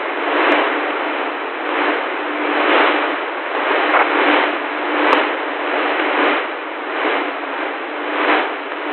radio_static.ogg